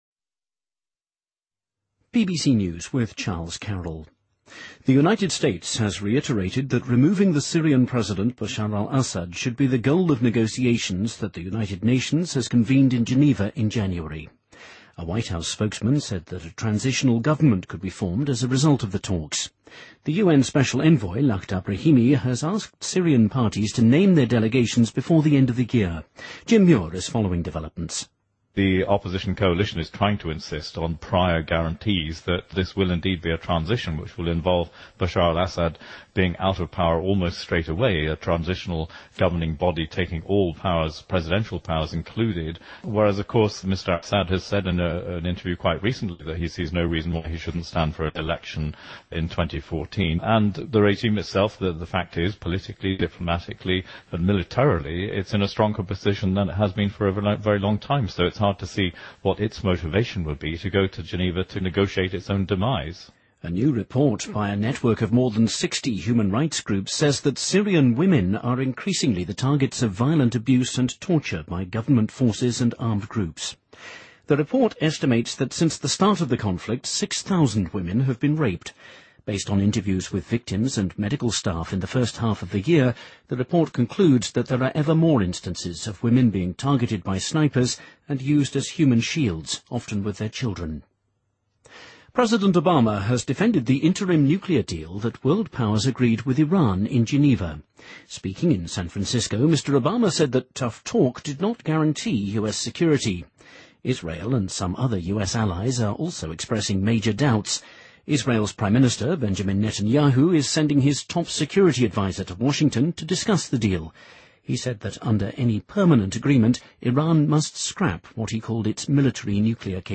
Date:2013-11-26Source:BBC Editor:BBC News